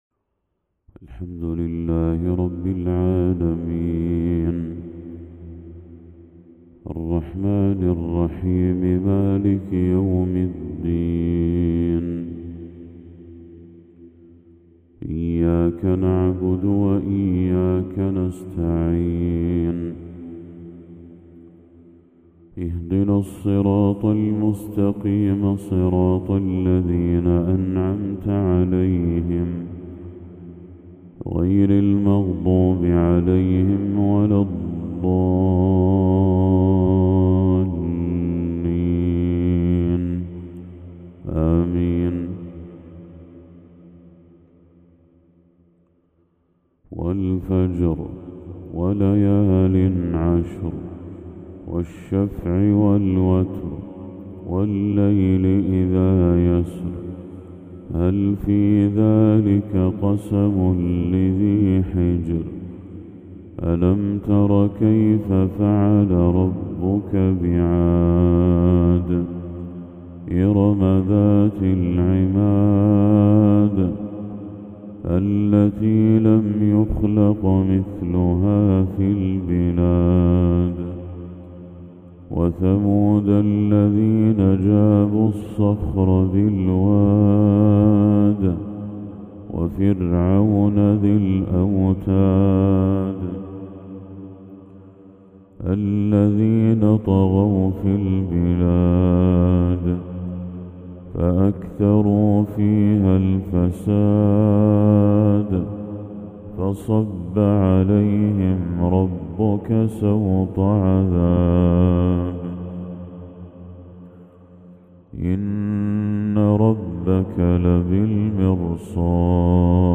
تلاوة لسورتي الفجر والبلد للشيخ بدر التركي | فجر1 ذو الحجة 1445هـ > 1445هـ > تلاوات الشيخ بدر التركي > المزيد - تلاوات الحرمين